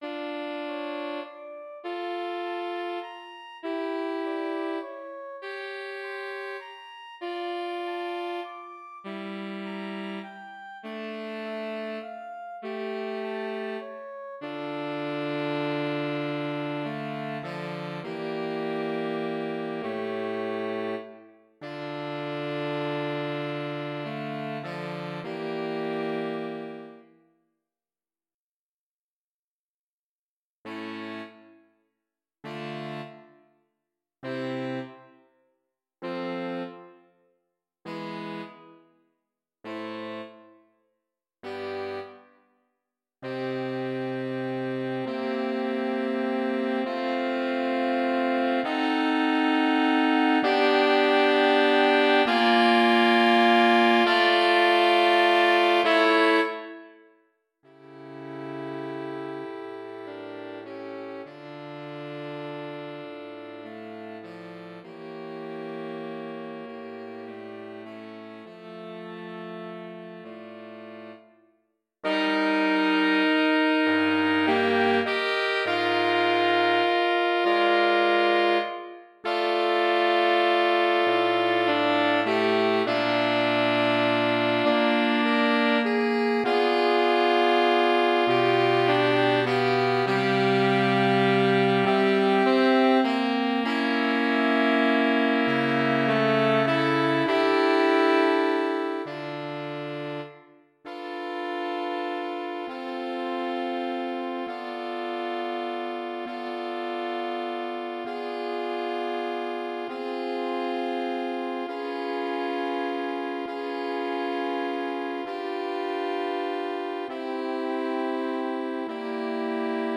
3 SATB Powerful. Sad but hopeful funereal piece.